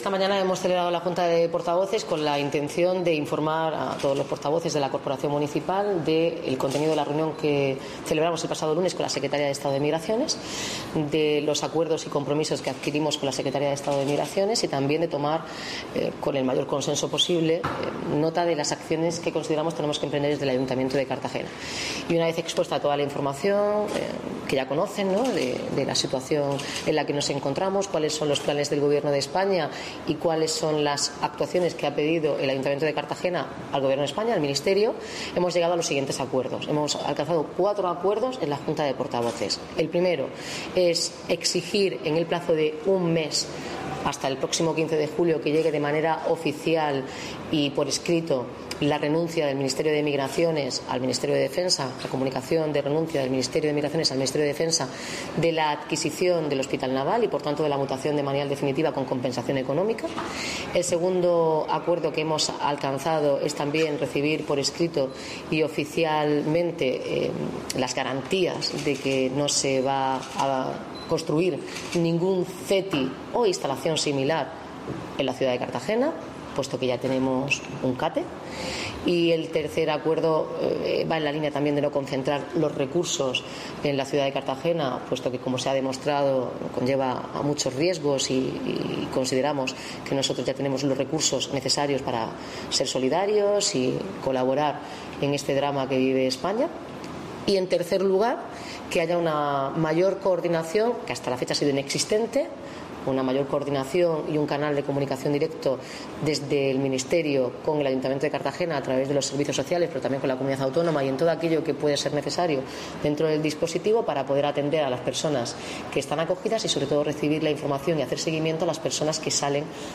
Enlace a Declaraciones de la alcaldesa sobre los acuerdos de la Junta de Portavoces en torno al CETI